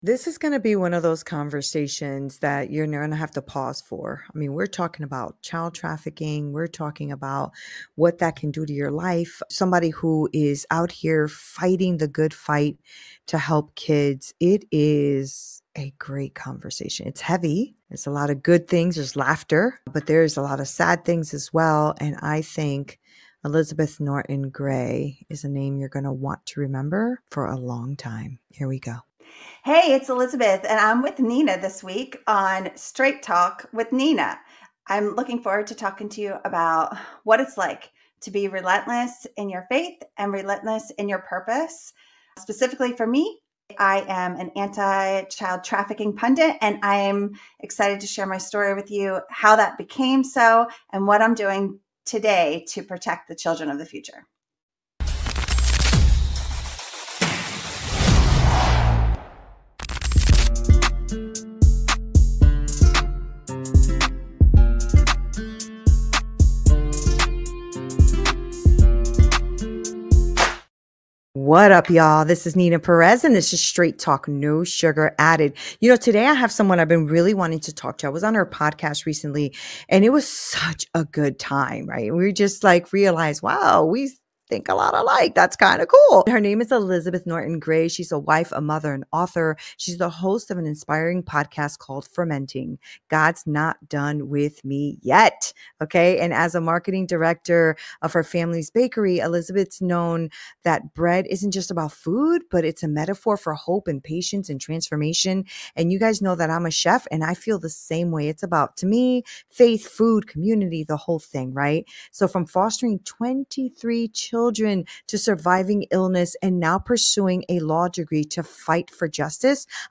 conversation